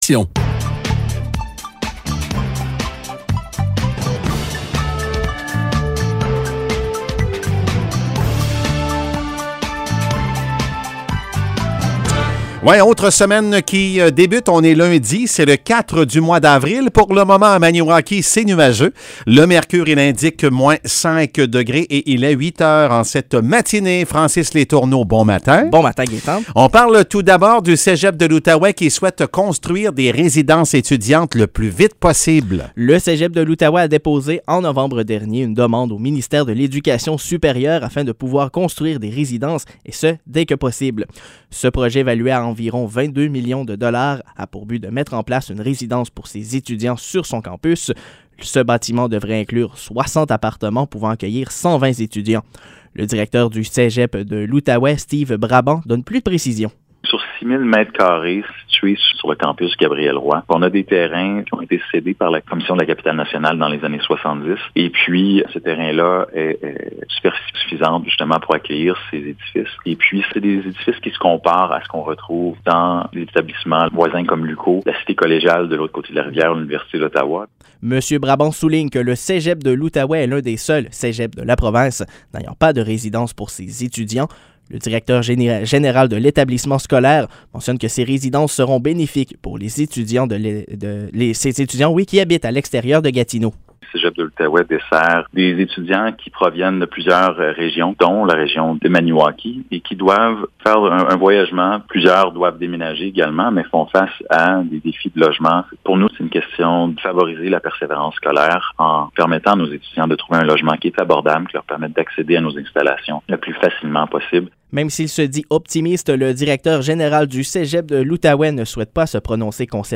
Nouvelles locales - 4 avril 2022 - 8 h